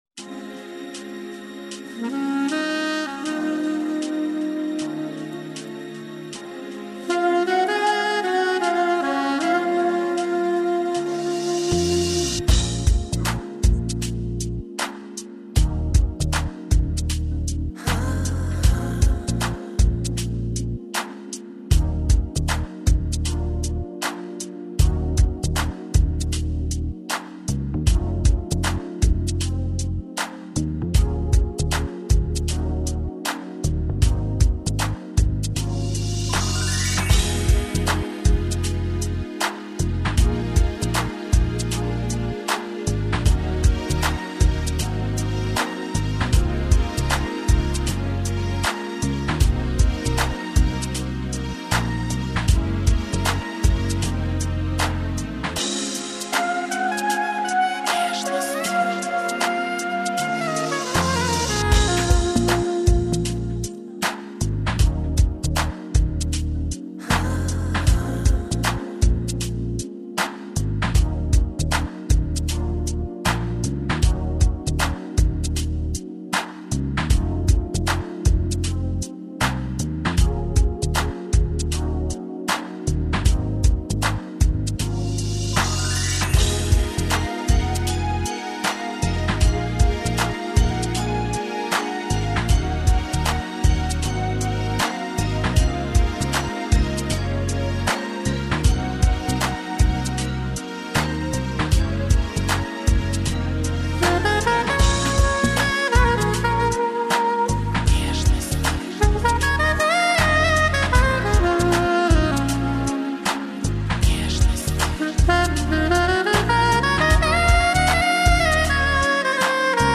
Пойте караоке